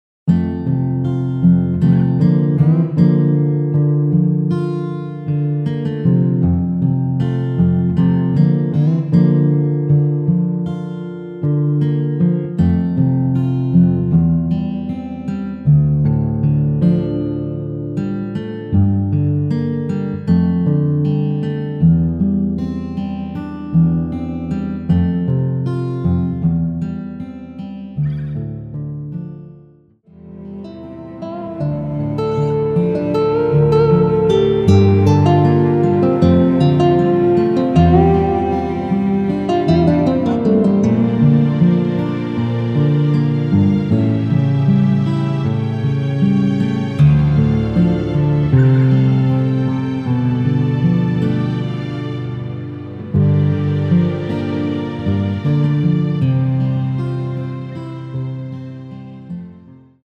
◈ 곡명 옆 (-1)은 반음 내림, (+1)은 반음 올림 입니다.
앞부분30초, 뒷부분30초씩 편집해서 올려 드리고 있습니다.
중간에 음이 끈어지고 다시 나오는 이유는